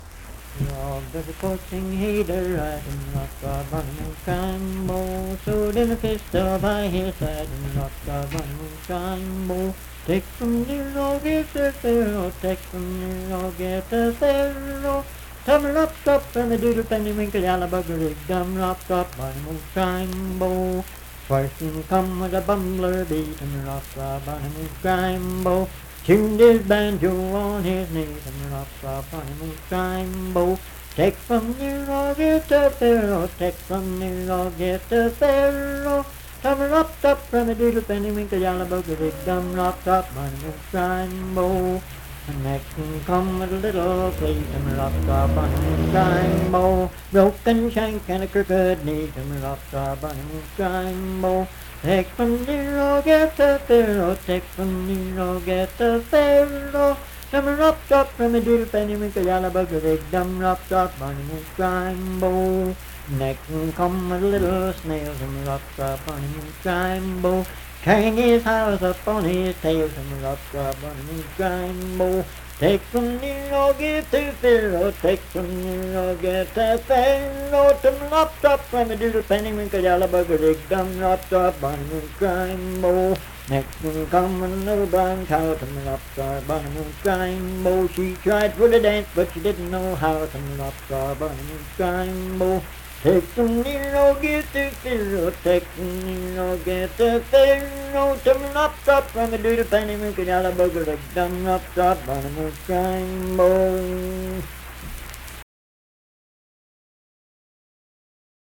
Unaccompanied vocal music
Performed in Dundon, Clay County, WV.
Children's Songs, Dance, Game, and Party Songs
Voice (sung)